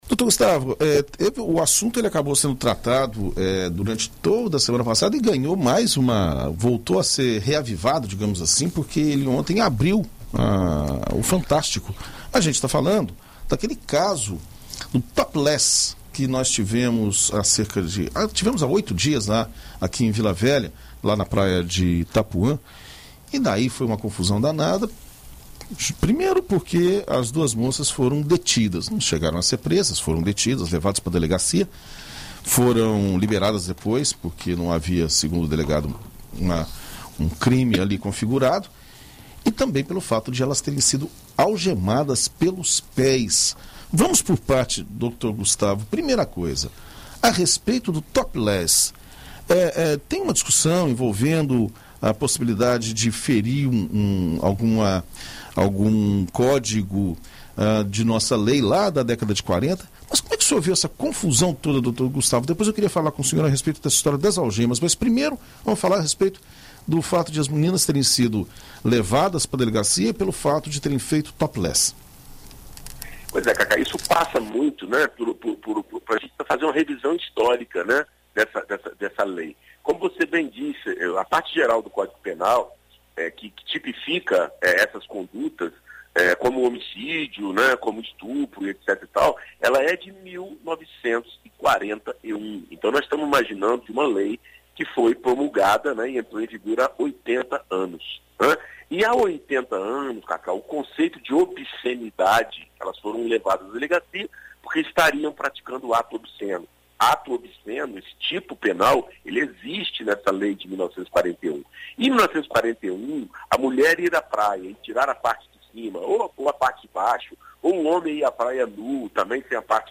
Na coluna Direito para Todos desta segunda-feira (07), na BandNews FM Espírito Santo